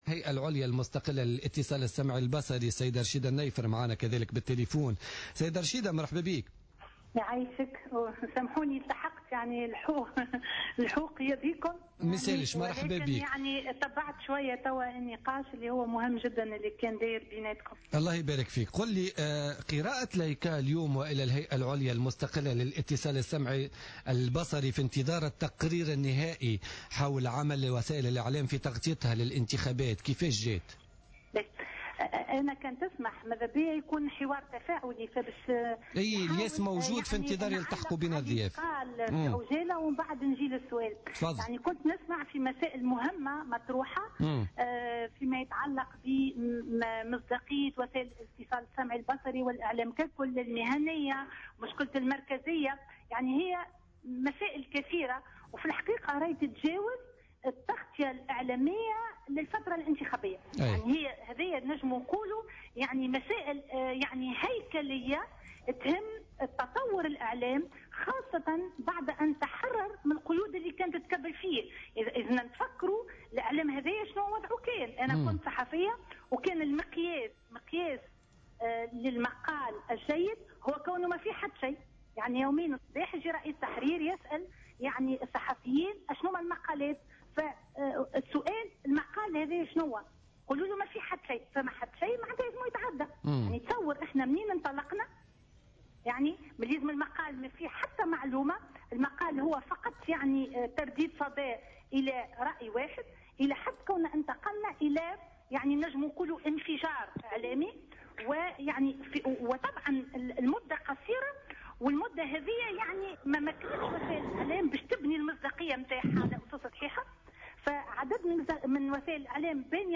قالت عضو الهيئة العليا المستقلة للإتصال السمعي البصري رشيدة النيفر في مداخلة لها في برنامج بوليتيكا اليوم الجمعة 28 نوفمبر 2014 أن الإعلام التونسي أمامه فرصة ذهبية للتخلص من مخلفات المنظومة السابقة لأن انظار العالم موجهة له لترى ادائه في فترة مهمة من تاريخ تونس من خلال تغطية اعلامية محايدة ومهنية تسمح لكل ناخب بالإختيار بكل حرية.